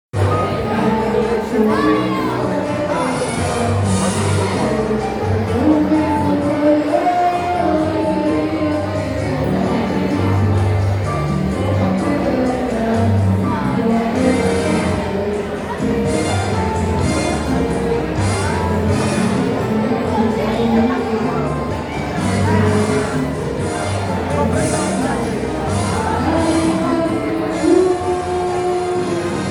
C’est une occasion unique de recréer, grâce aux sons enregistrés dans un lieu, l’atmosphère singulière que celui-ci possède.
Ainsi, grâce à cette première carte postale sonore, les élèves de Bellevue et leur deux accompagnateurs  nous permettent de voyager virtuellement et de découvrir une récréation festive en musique, comme souvent à l’instituto tecnológico Santiago de Arma de Rionegro :
Une récréation banale al colegio !